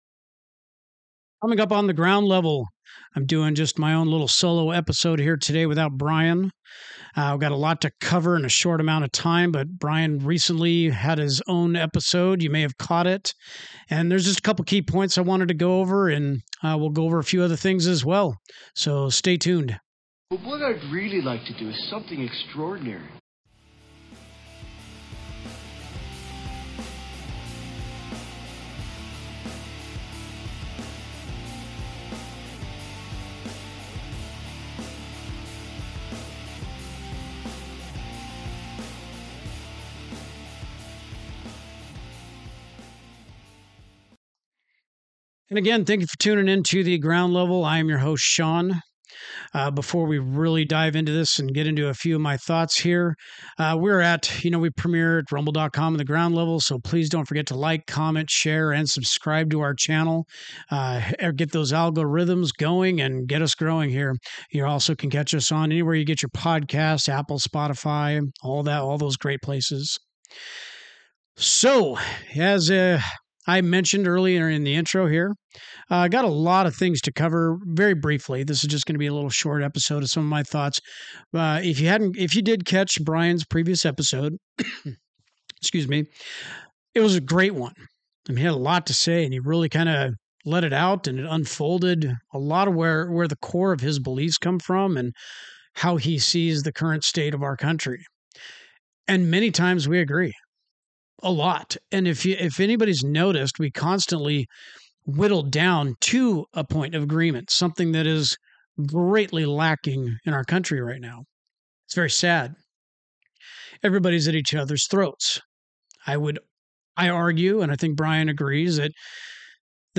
Speaking my mind with any American who wants to chat over drinks and discuss current events, politics, pop culture, pretty much anything.